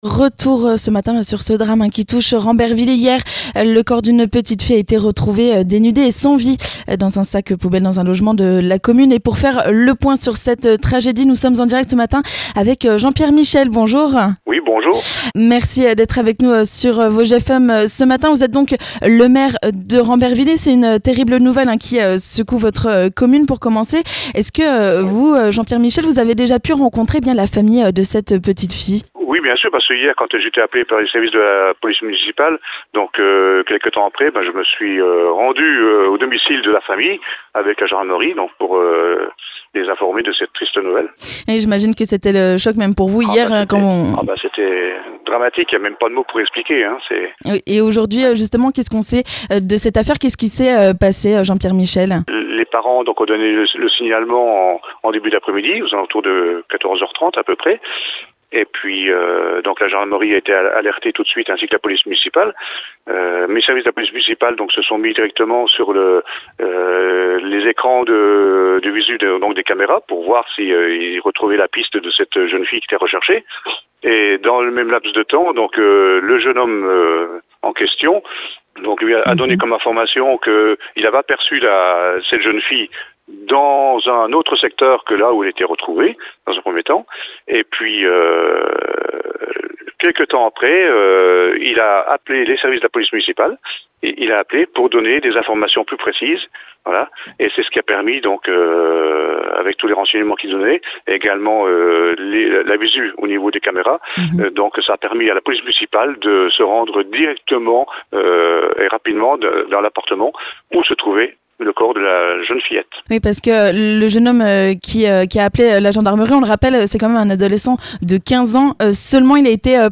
La réaction du maire de Rambervillers, en direct ce mercredi matin sur Vosges FM, Jean-Pierre Michel.